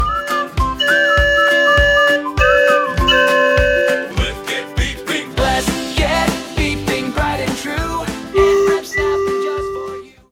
(jingle)